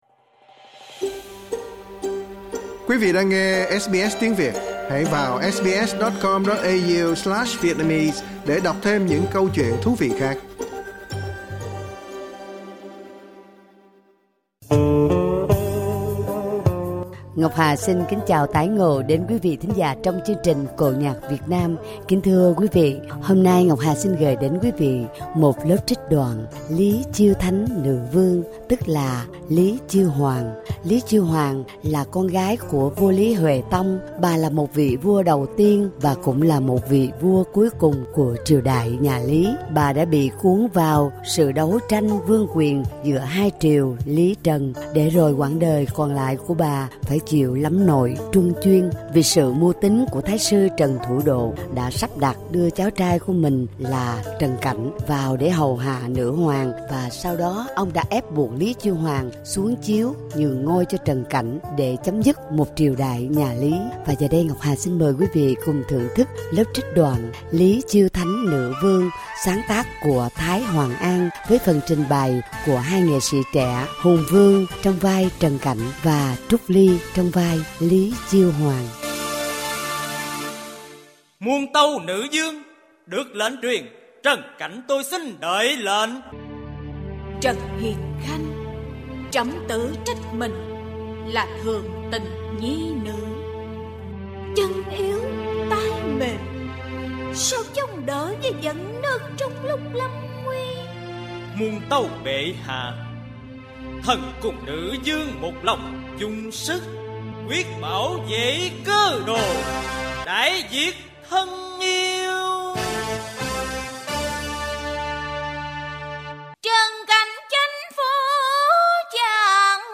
Vọng cổ